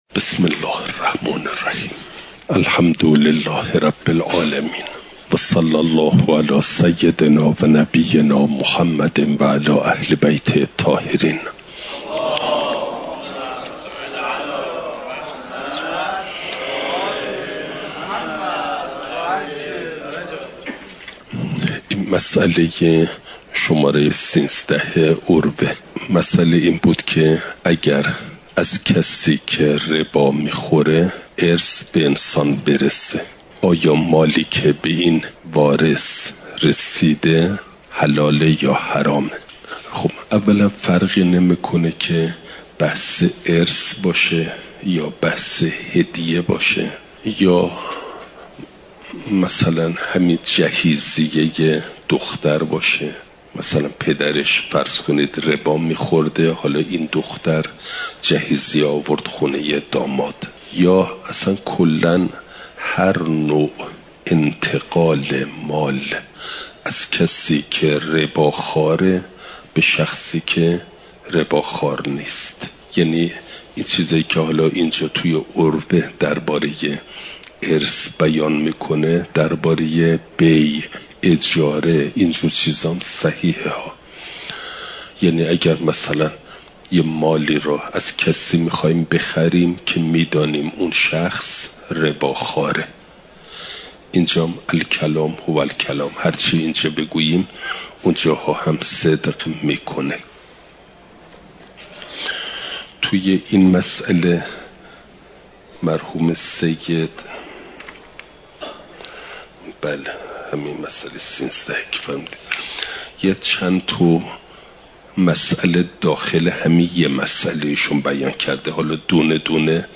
نظام اقتصادی اسلام؛ مبحث ربا (جلسه۵۷) « دروس استاد